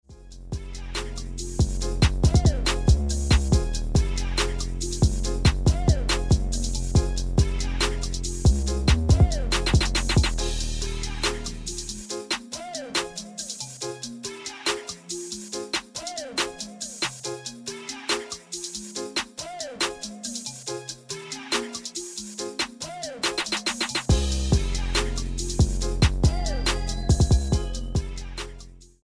STYLE: Hip-Hop
Instrumental